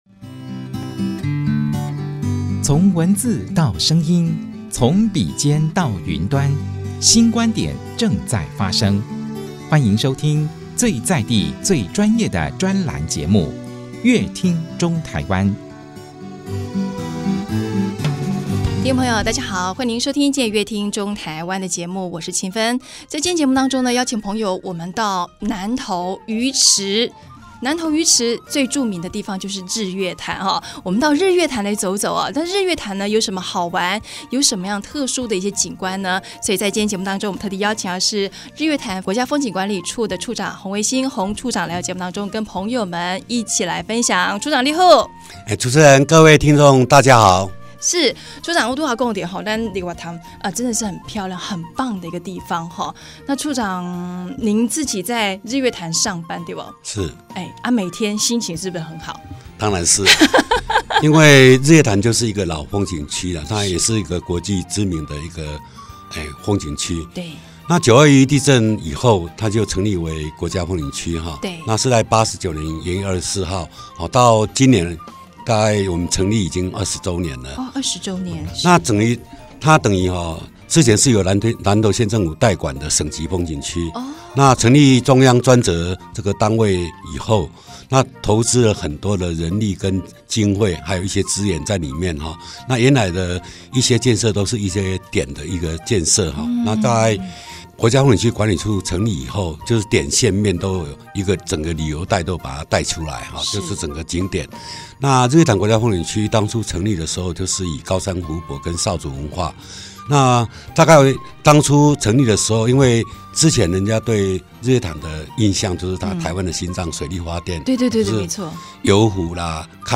本集來賓：交通部觀光局日月潭國家風景區管理處洪維新處長 本集主題：「在地人帶路~看見日月潭之美」 本集內容：